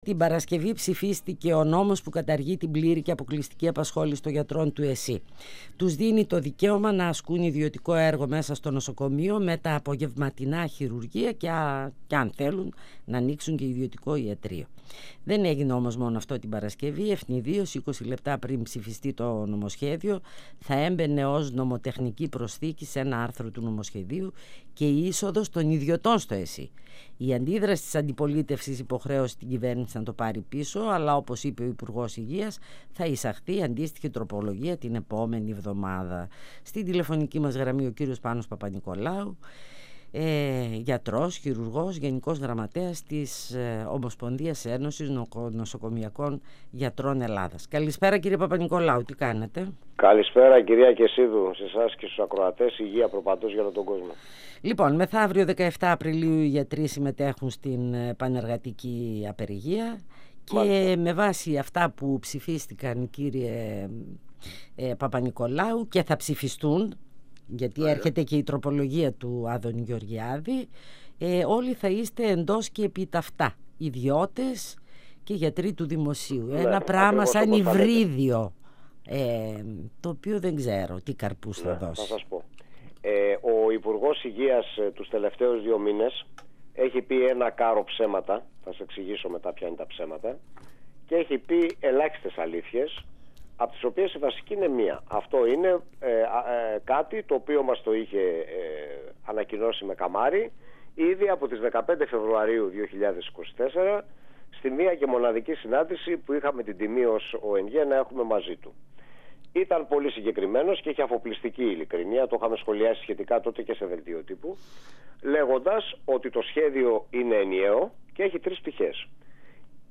Φωνες Πισω απο τη Μασκα Συνεντεύξεις